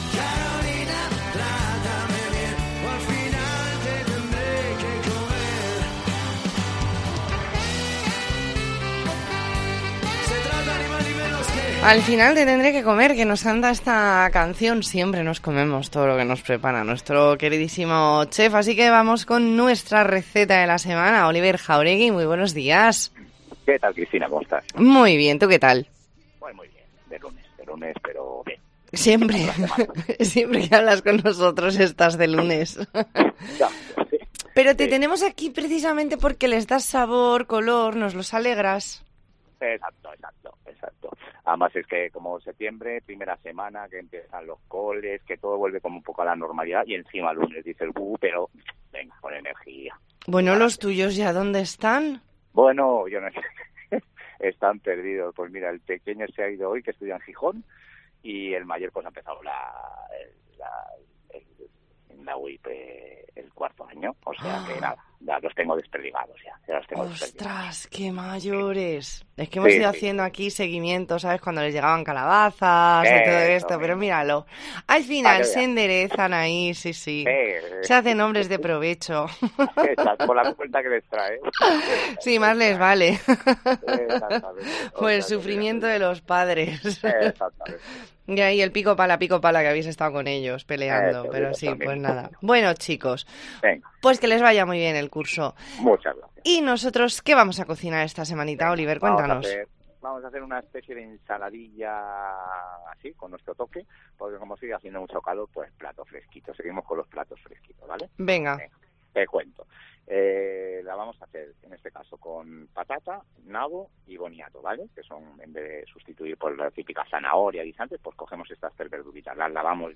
Entrevista en La Mañana en COPE Más Mallorca, lunes 12 de septiembre de 2022.